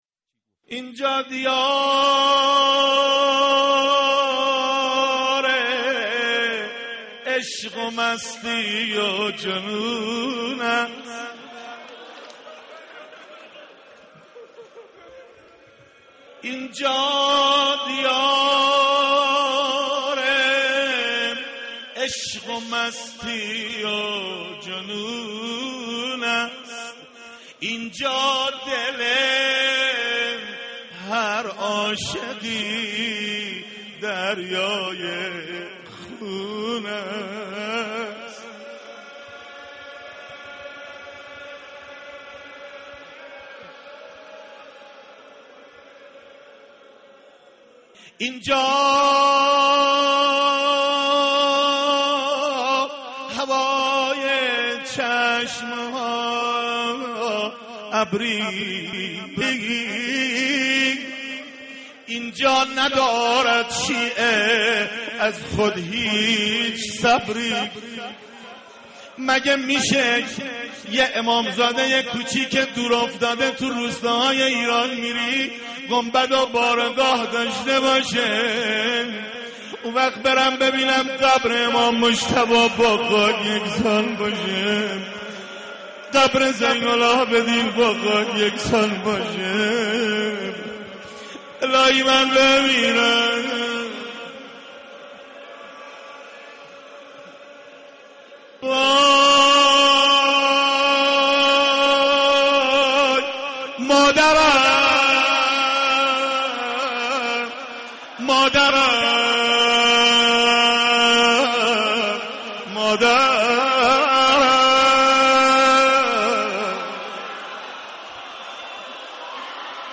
سالروز تخریب قبرستان بقیع/اینجا دیار عشق و مستی و جنون است(روضه